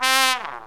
SYNTH GENERAL-4 0005.wav